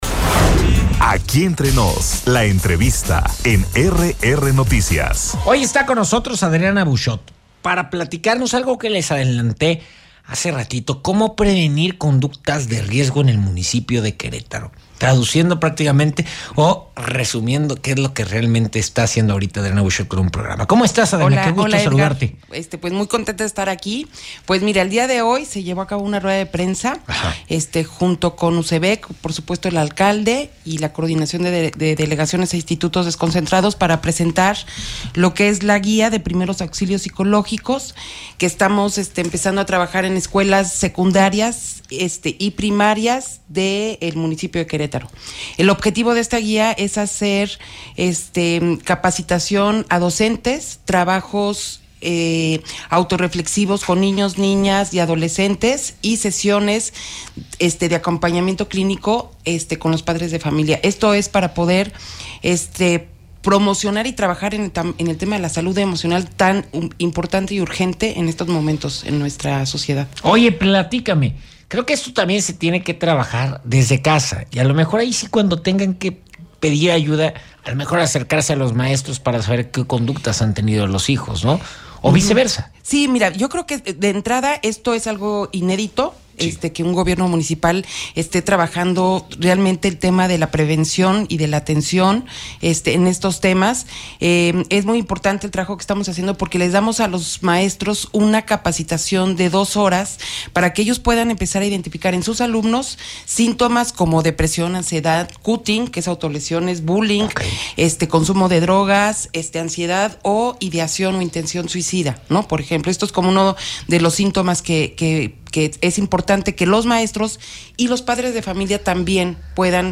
EntrevistasMultimediaMunicipioPodcastQuerétaro